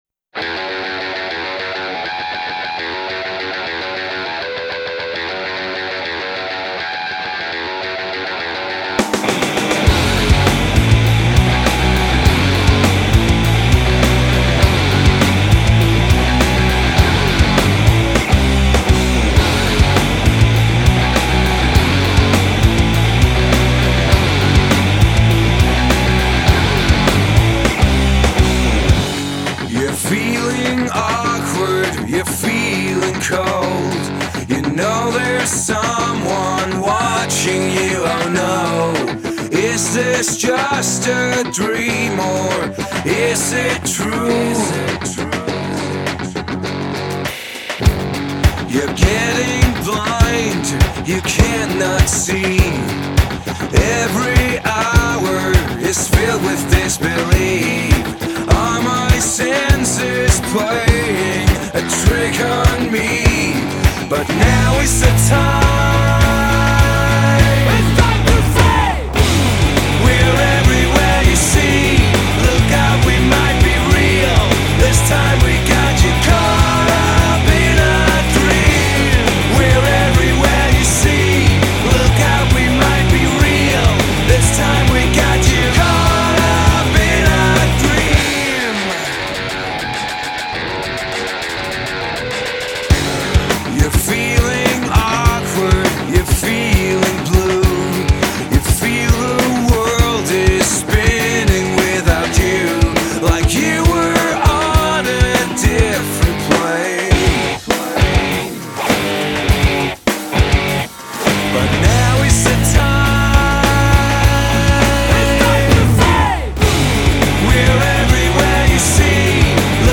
Single
Vocals